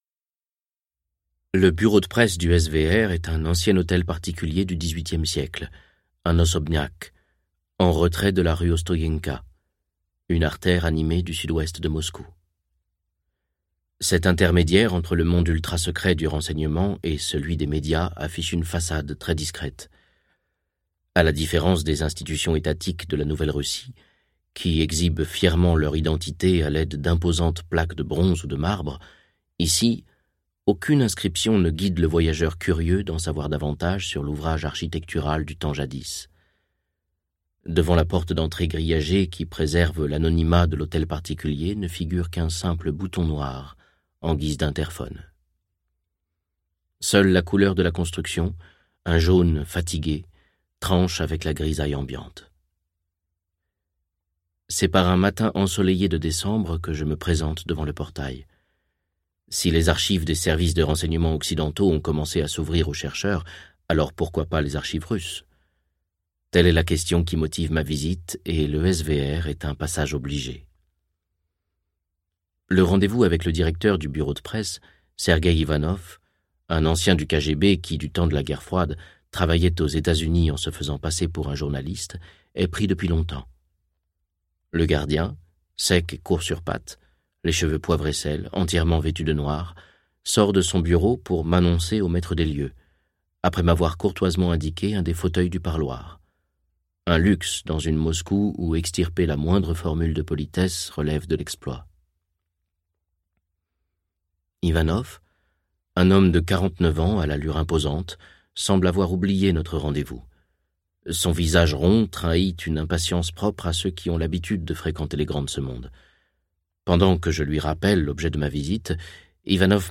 Texte : Intégral